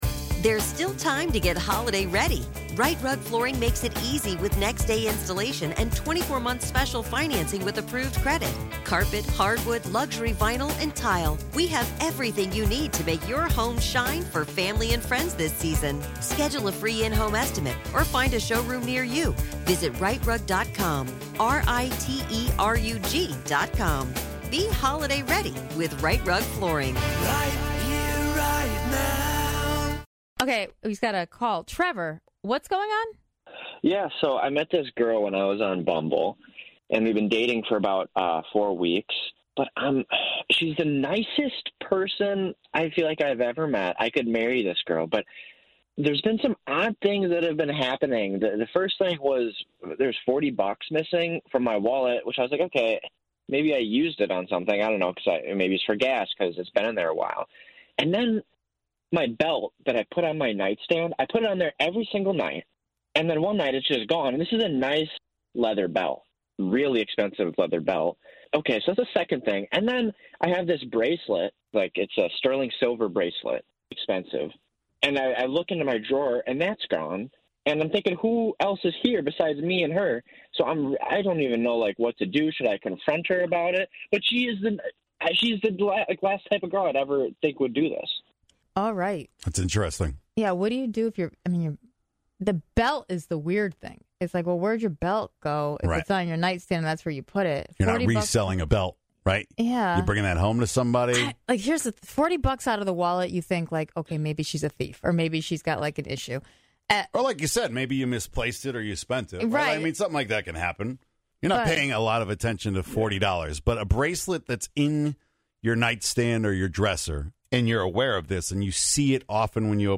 received a call from a man who needed some advice